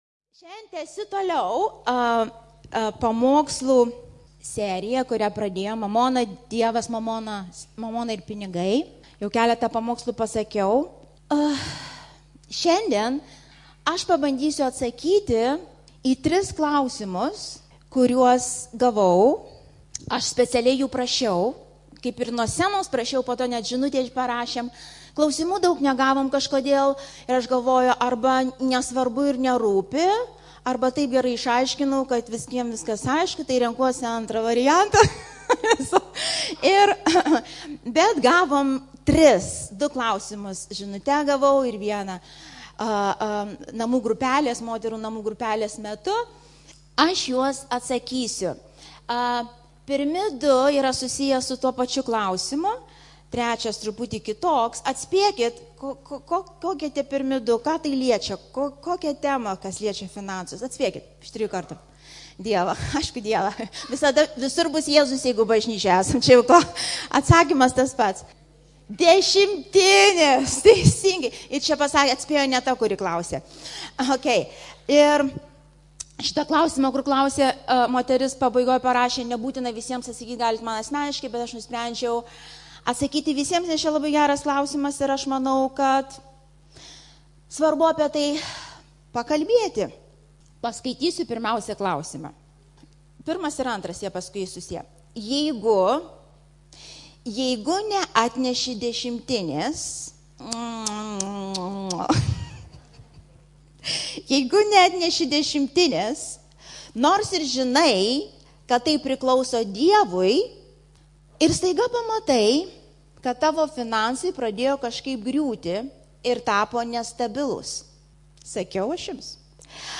PAMOKSLO ĮRAŠAS MP3